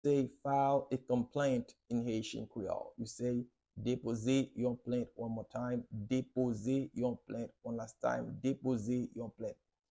How to say “File a Complaint” in Haitian Creole - “Depoze yon plent” pronunciation by a native Haitian Teacher
“Depoze yon plent” Pronunciation in Haitian Creole by a native Haitian can be heard in the audio here or in the video below:
How-to-say-File-a-Complaint-in-Haitian-Creole-Depoze-yon-plent-pronunciation-by-a-native-Haitian-Teacher.mp3